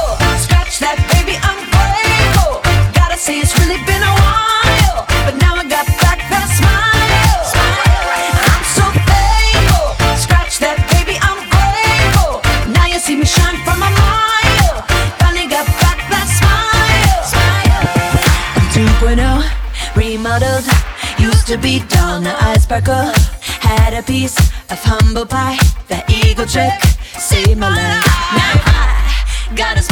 • Pop
nu-disco song